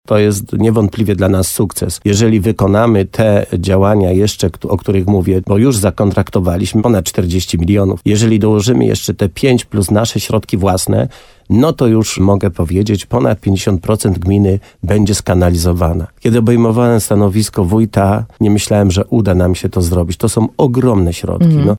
Jak zdradził wójt Leszek Skowron w rozmowie z RDN Nowy Sącz, Korzenna znalazła się na liście rankingowej.